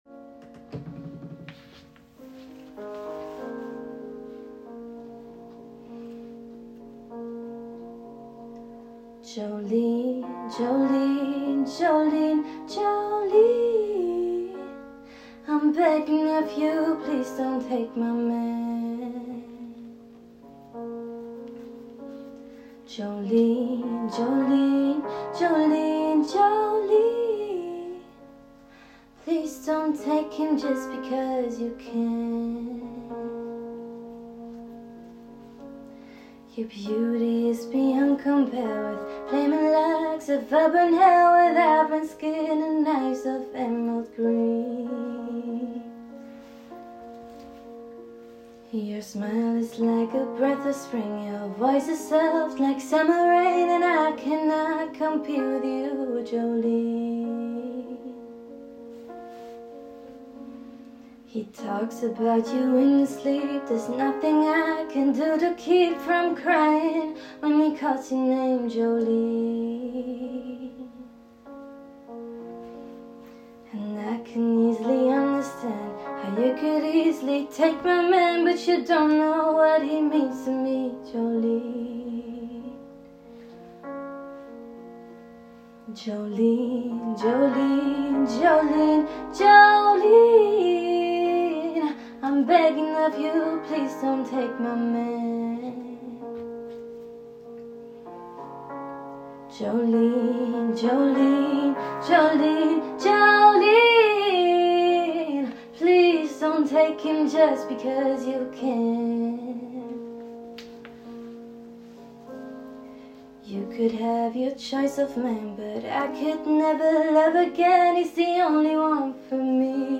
Sängerin sucht Band Luzern